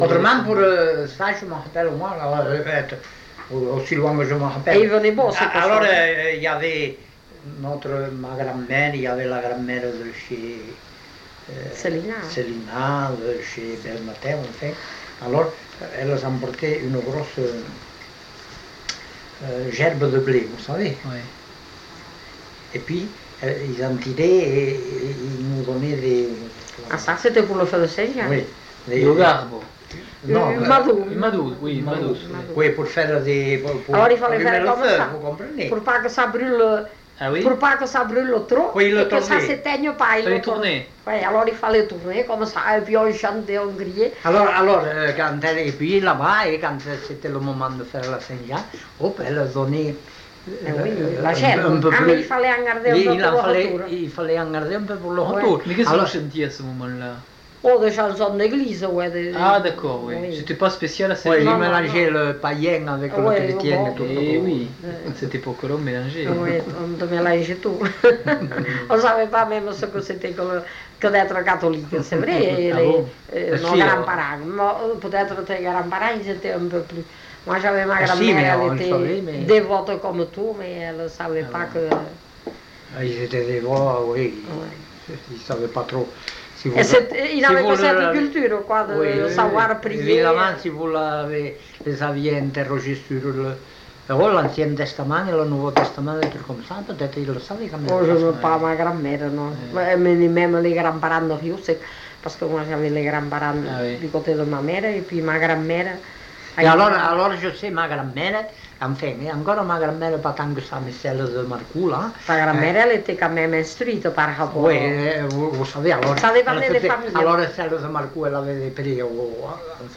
Aire culturelle : Couserans
Genre : témoignage thématique
Ecouter-voir : archives sonores en ligne